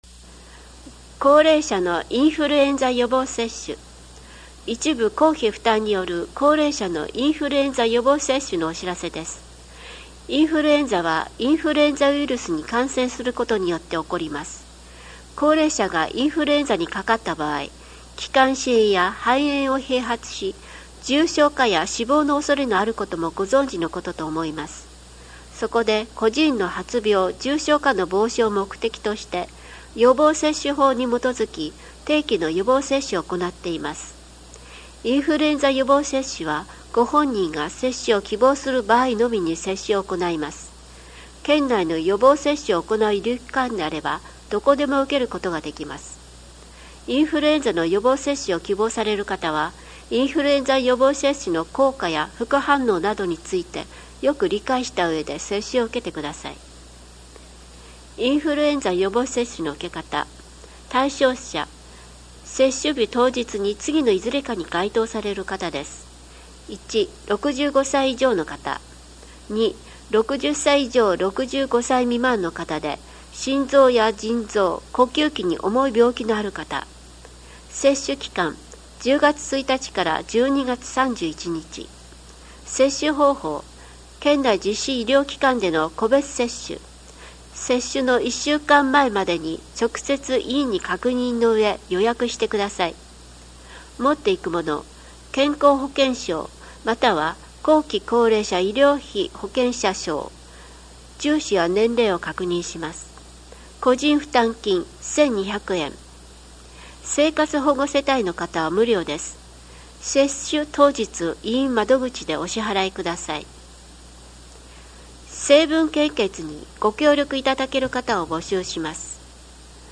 また、音訳ボランティア「エポカル武雄フレンズ」のご協力により、音読データをMP3形式で提供しています。